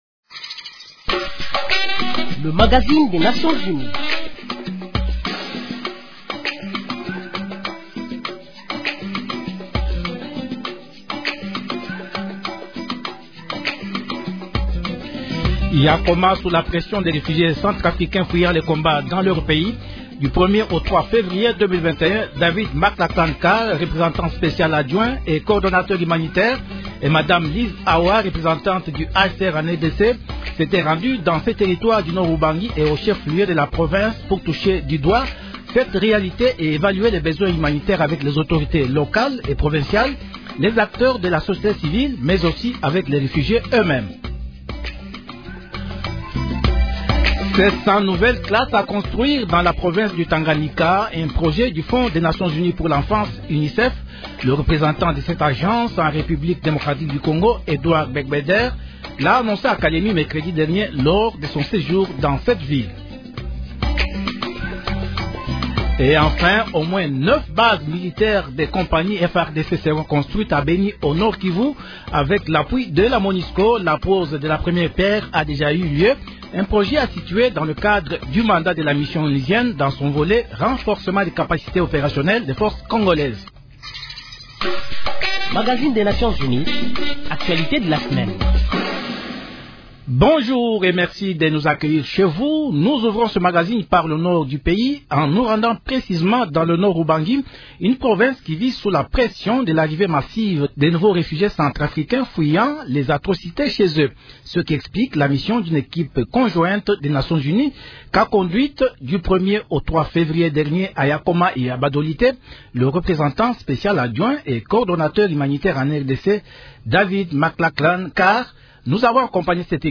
Décryptage
Brèves *En plus bref, c’est jeudi 4 février 2021 que Mme Leila Zerrougui a quitté la RDC après avoir terminé son mandat à la tête de la Monusco.